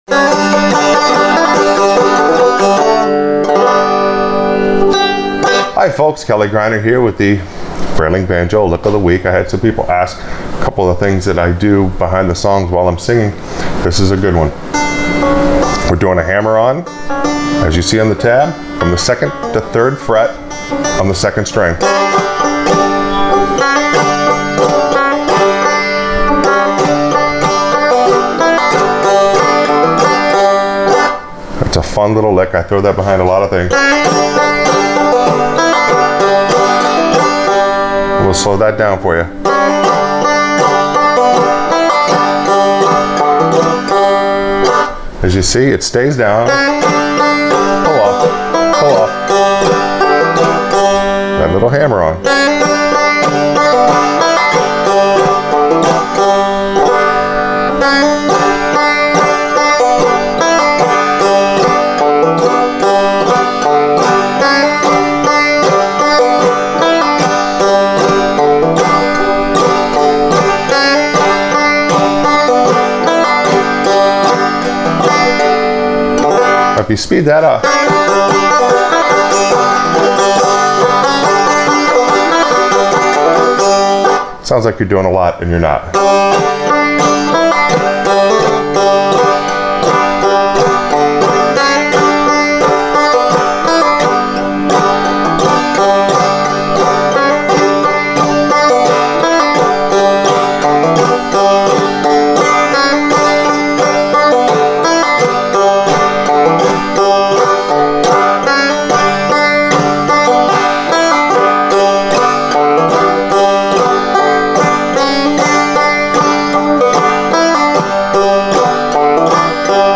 Frailing Banjo Lick Of The Week – Backup Lick